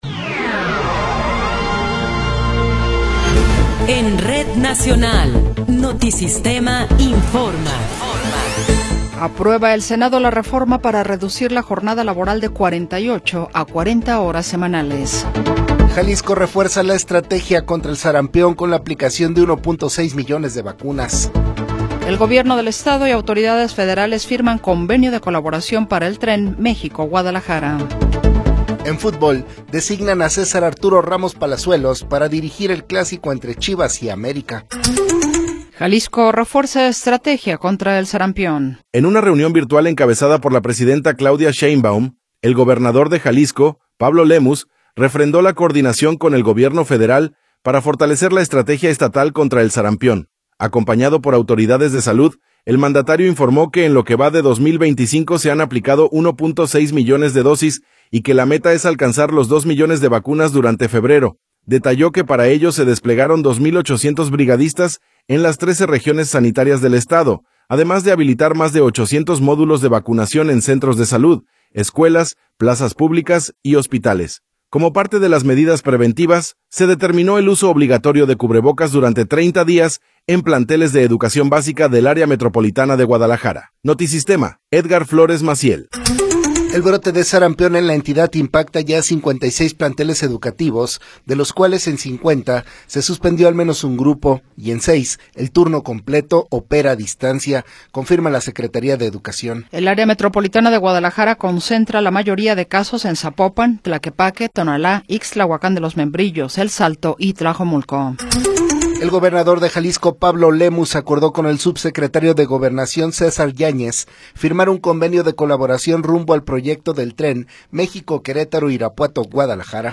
Noticiero 20 hrs. – 11 de Febrero de 2026
Resumen informativo Notisistema, la mejor y más completa información cada hora en la hora.